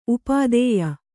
♪ upādēya